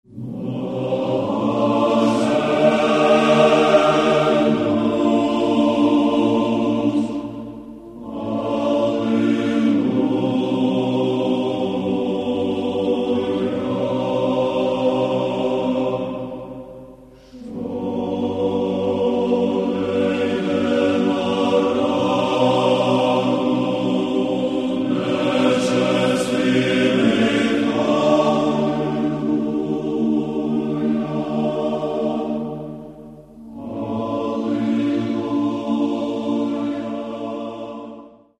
Церковная